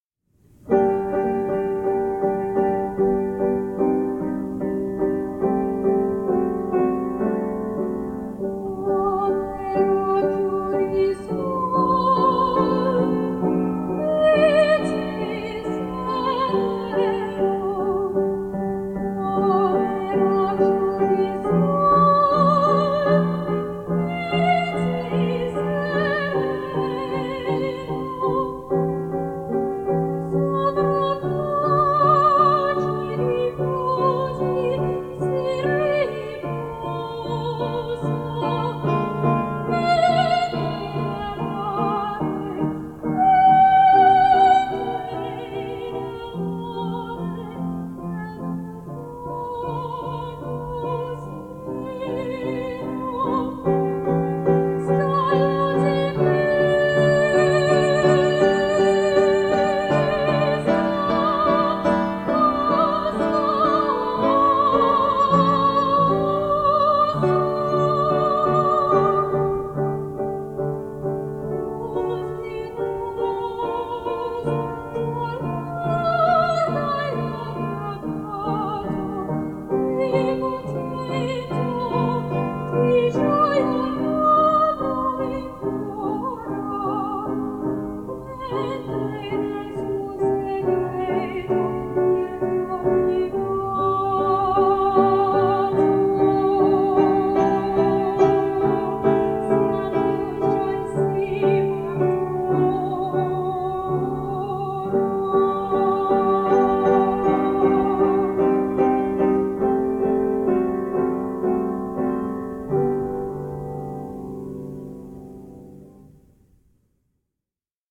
I am a soprano.
(Listen out for the green singing finch in the back (and often fore) ground. He used to sit beside the piano and join in!)
Please accept my apologies for the often inadequate piano playing, and the unprofessional quality of recording.